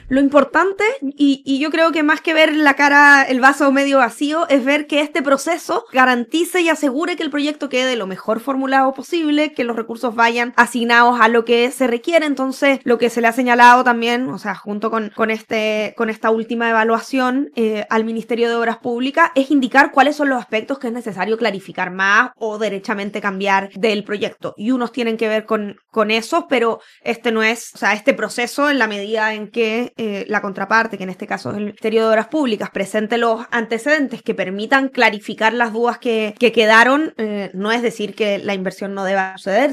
En conversación con Radio Paulina, la ministra de Desarrollo Social, Javiera Toro, explicó que el proceso de evaluación forma parte del rol que cumple la cartera en la revisión de los proyectos de inversión pública.